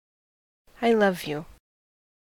Ääntäminen
US : IPA : /aɪ ˈlʌv juː/